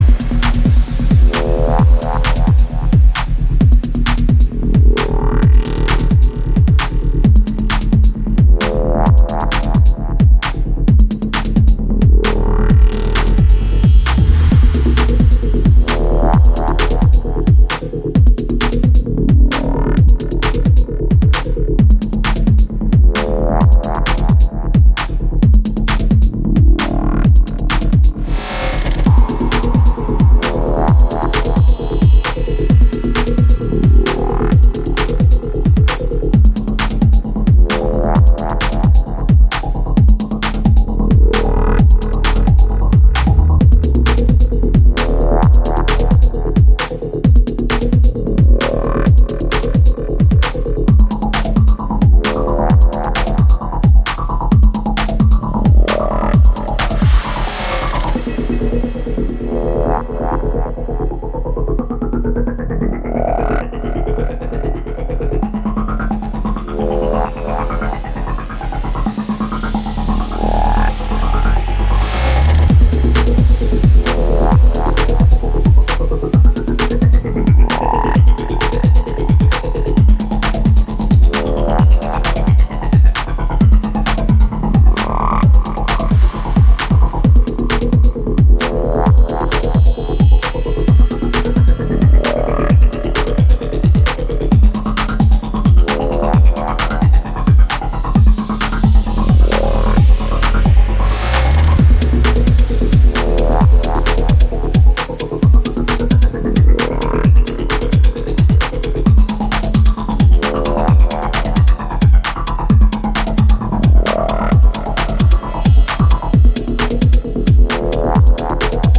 じっくり煮詰めるエレクトロ
ディープにハメてくる感覚が以前よりも研ぎ澄まされてきてると感じさせます。